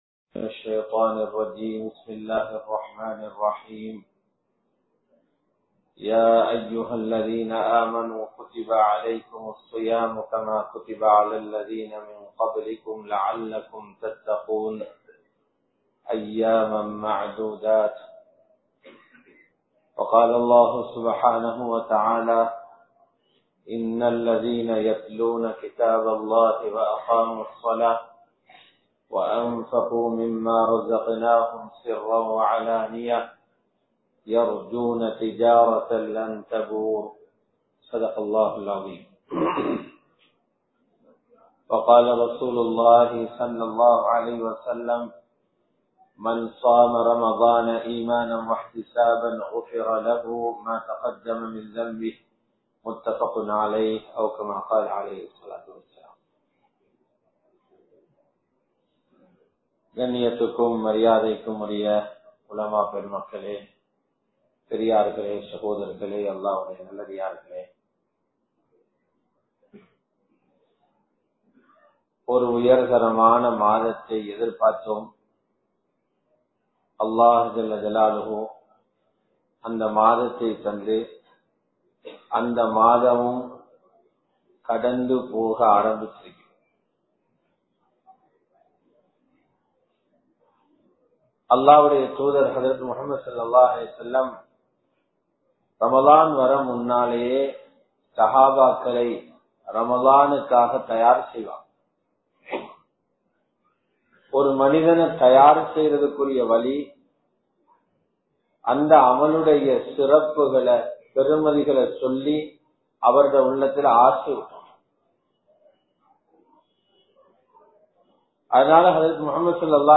உங்களின் சுவர்க்கத்தை பறிப்பவைகள் | Audio Bayans | All Ceylon Muslim Youth Community | Addalaichenai